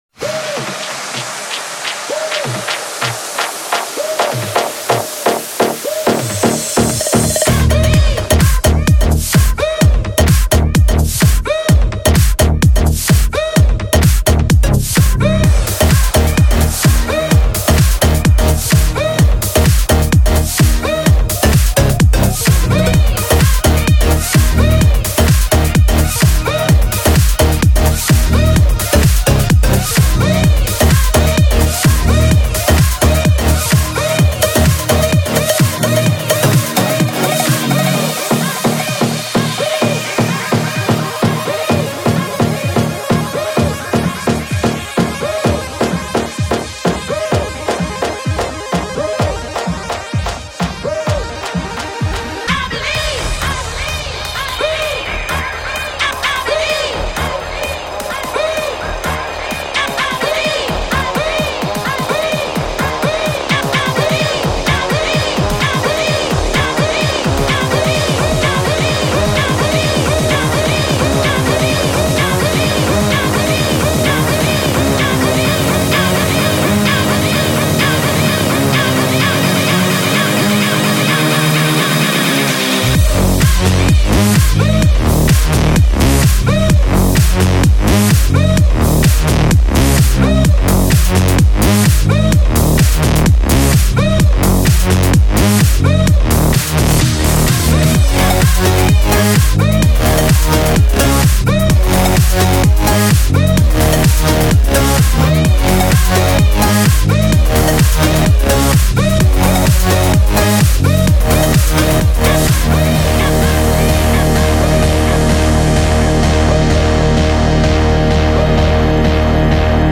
Жанр:Новогодний/Позитивный/Club/Dance